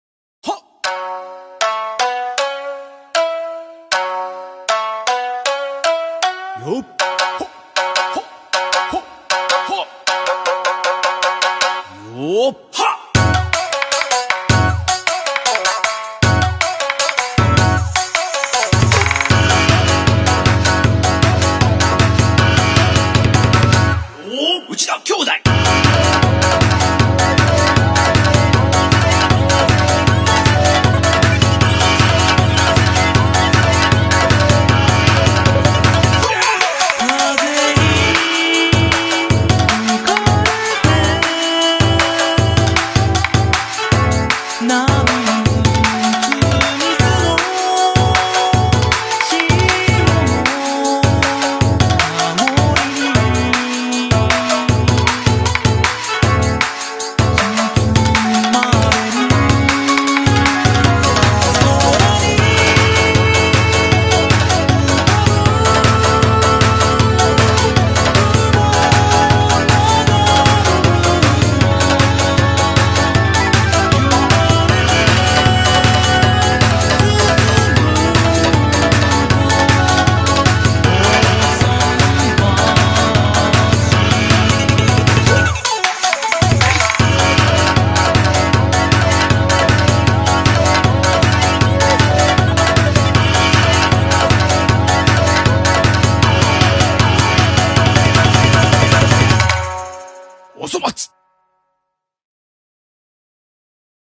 BPM156
Genre: SHAMISEN BROTHERS
A remix